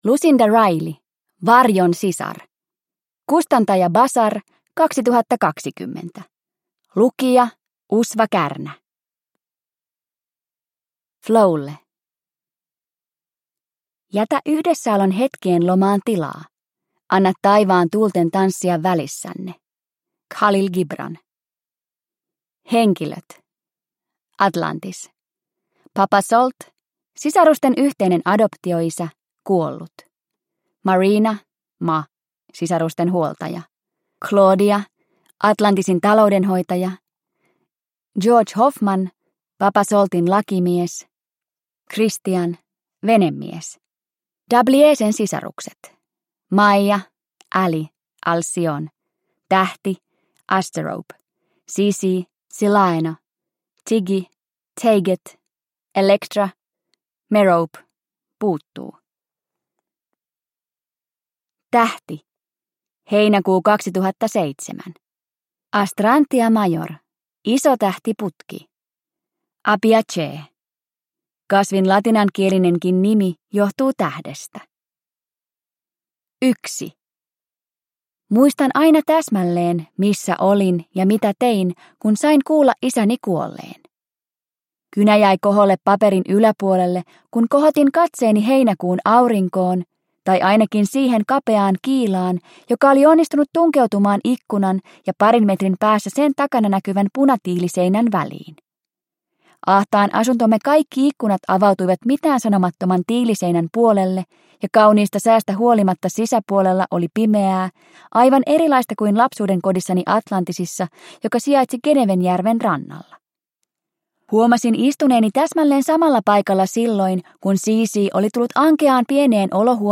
Varjon sisar – Ljudbok – Laddas ner